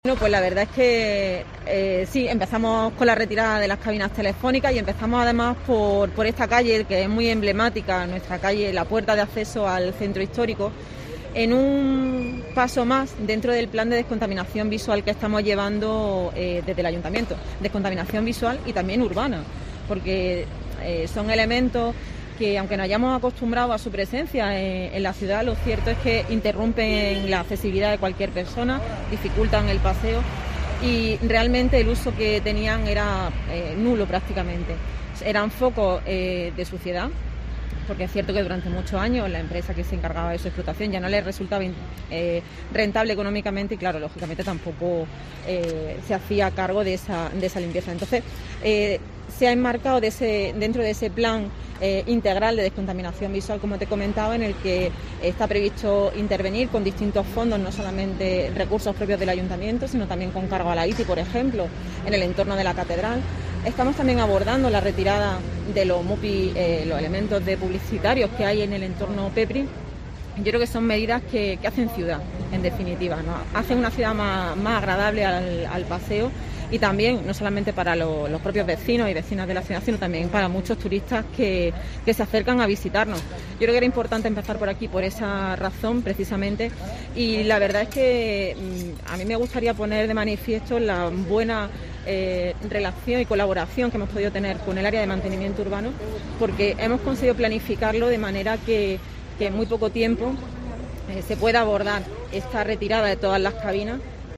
África Colomo, concejal del Ayuntamiento de Jaén